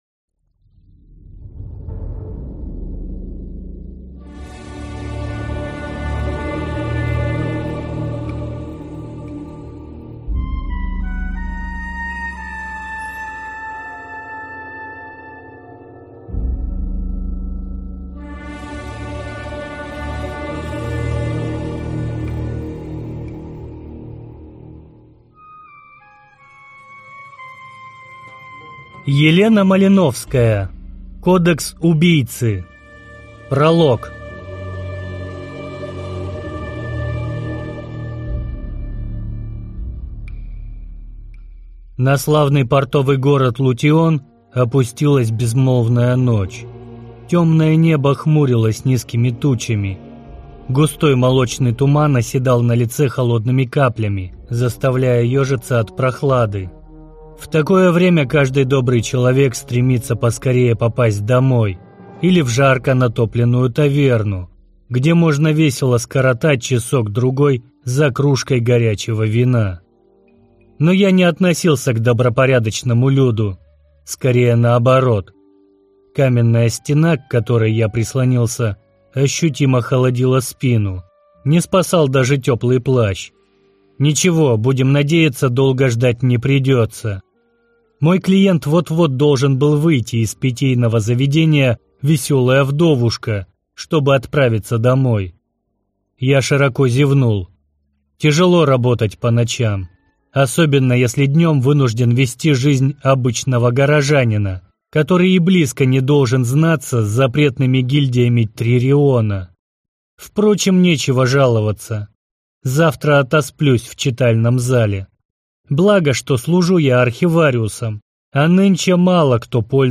Аудиокнига Кодекс убийцы | Библиотека аудиокниг